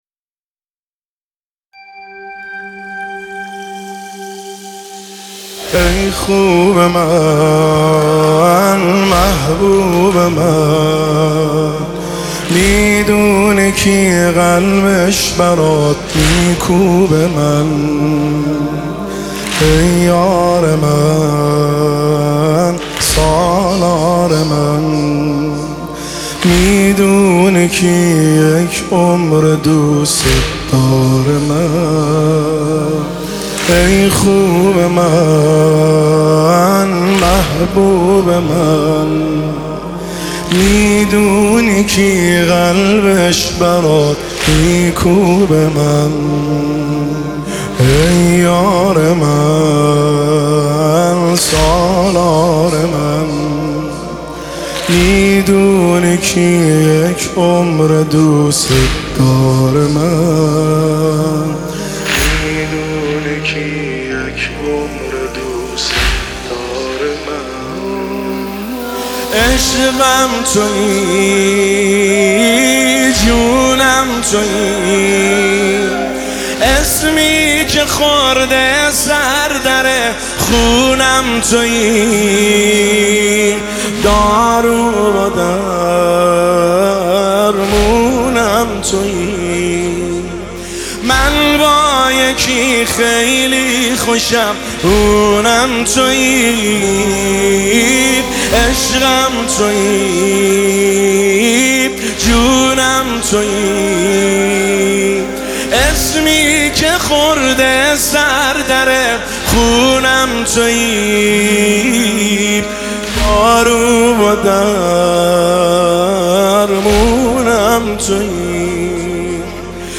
نماهنگ دلنشین
مداحی